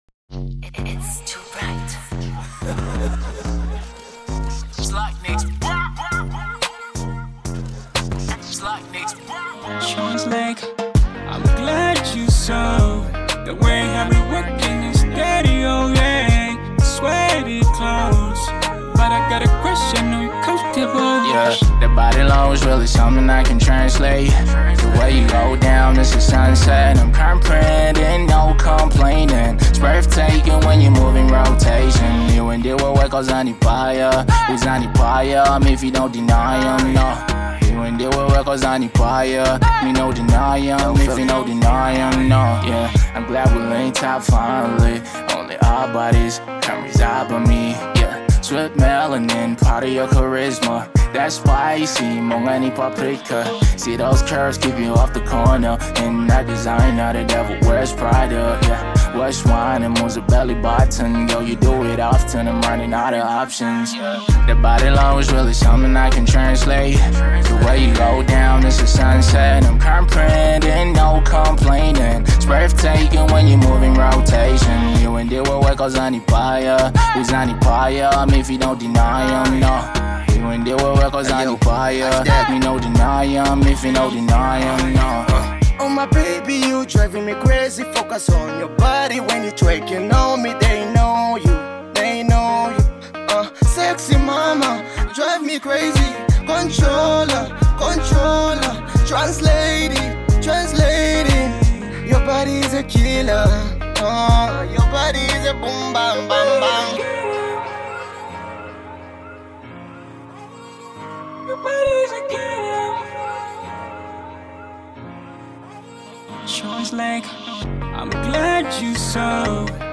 Afro jam
massive banger